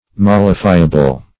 \Mol"li*fi`a*ble\